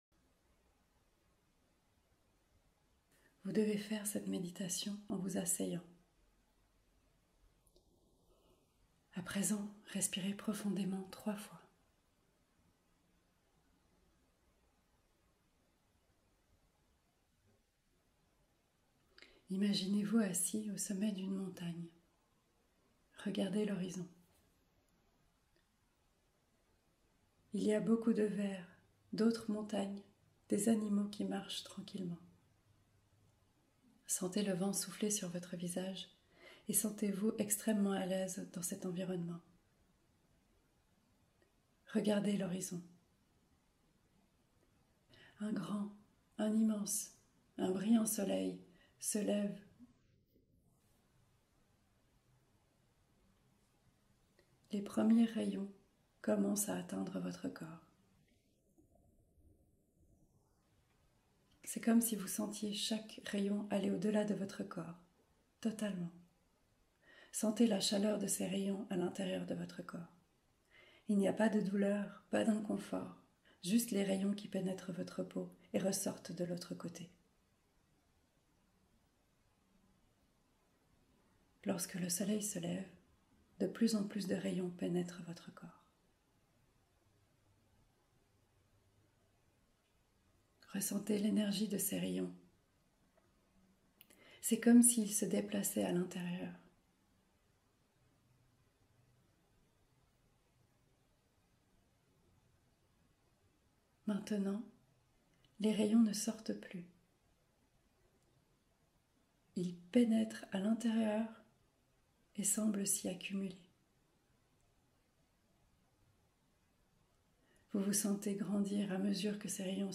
Méditation - sans_pub